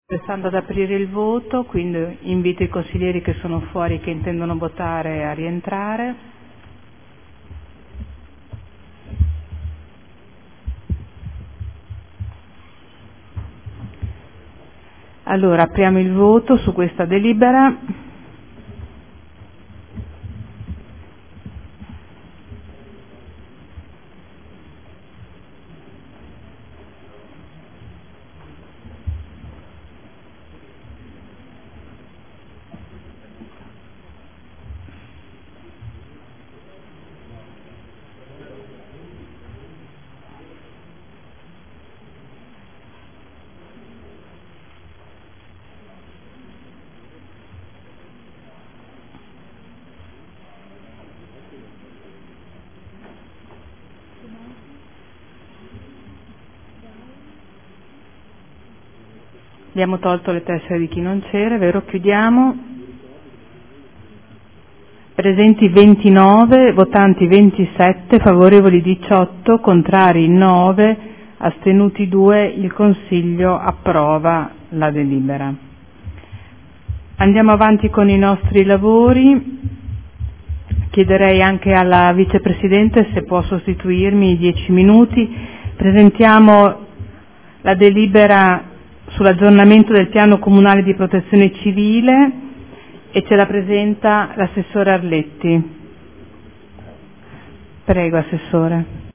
Seduta del 20/03/2014 Mette ai voti. Variante al Piano Strutturale Comunale (PSC) – Area ubicata tra Tangenziale, Strada Ponte Alto e Stradello Anesino – Zona elementare 2050 – Area 01.